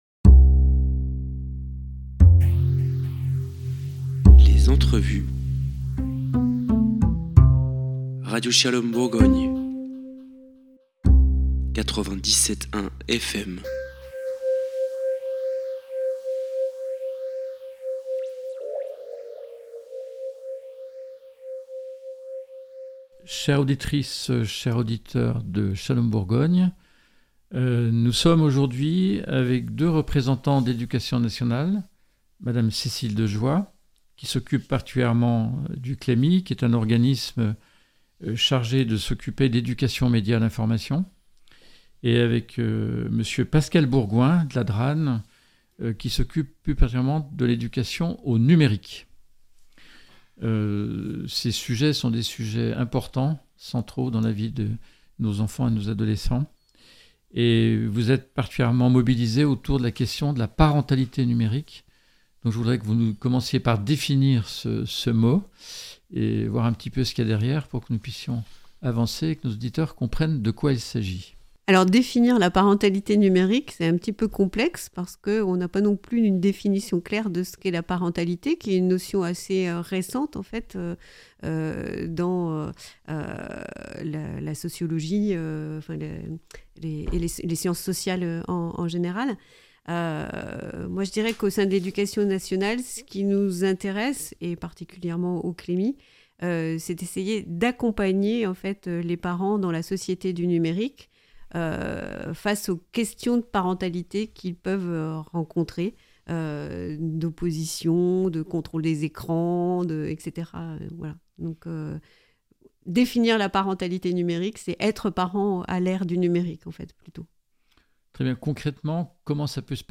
« Shalom Bourgogne » reçoit trois acteurs de l’Académie de Côted’Or autour de la Parentalité Numérique :